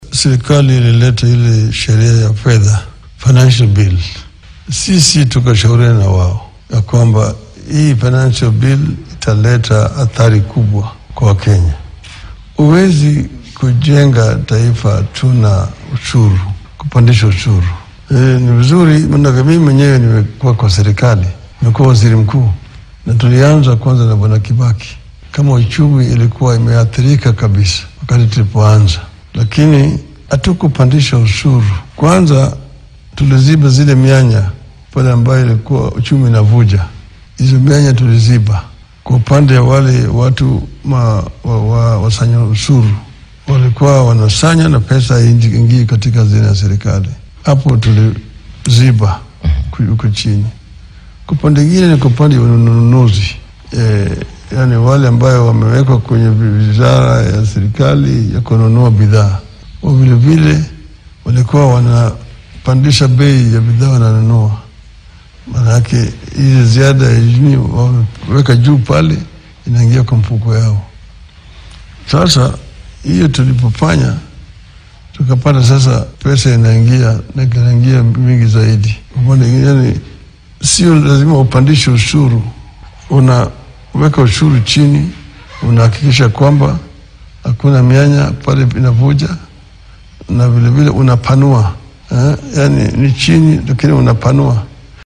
Hoggaamiyaha isbeheysiga mucaaradka ee Azimio La Umoja-One Kenya ,Raila Odinga oo wareysi bixiyay ayaa maamulka talada dalka haya ee Kenya Kwanza ku dhaliilay canshuurta dheeraadka ah ee uu soo rogay. Waxaa uu carrabka ku adkeeyay in aan dal lagu dhisi karin canshuur shacabka lagu kordhiyo.